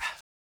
Breath Rnb.wav